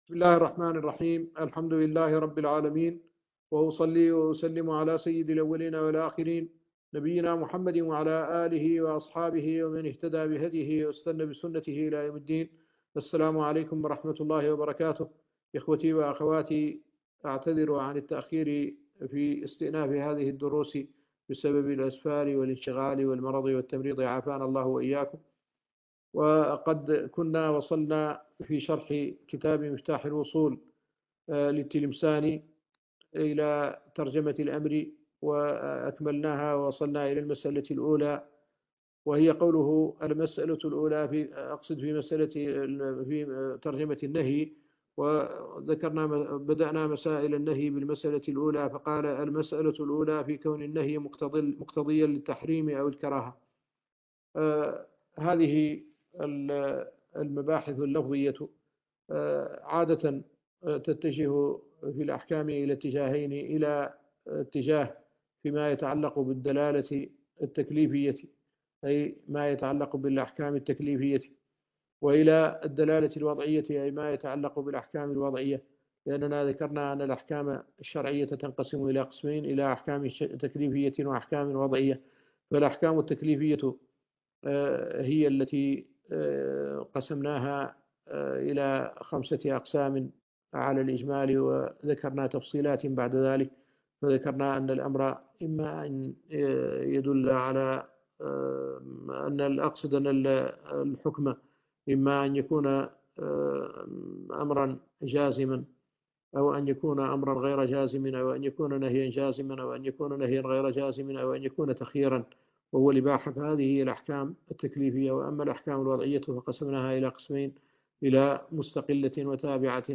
الدرس العاشر - شرح مفتاح الوصول إلى بناء الفروع على الأصول